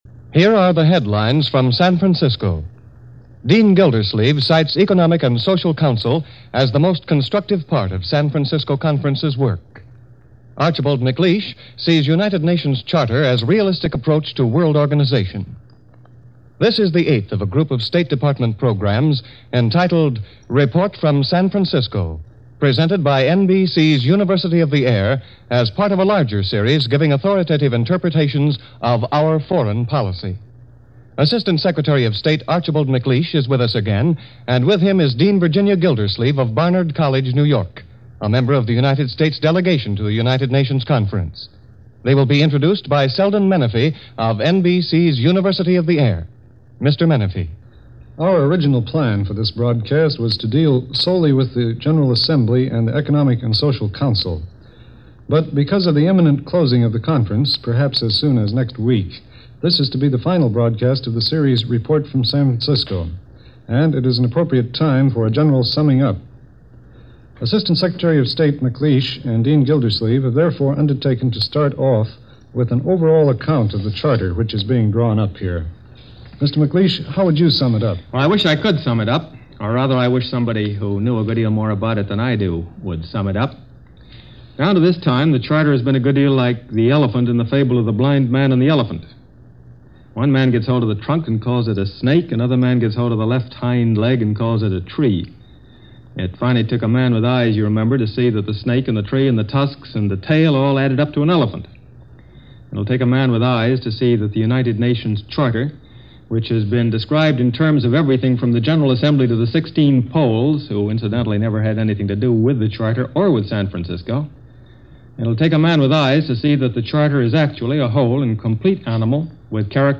June 16, 1945 - With An Eye On Human Rights - San Francisco Conference - Post-War Europe - Report from United Nations.